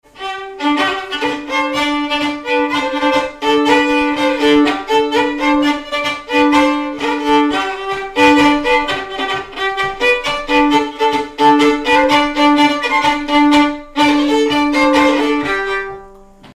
violon
accordéon chromatique
danse : polka des bébés ou badoise
Pièce musicale inédite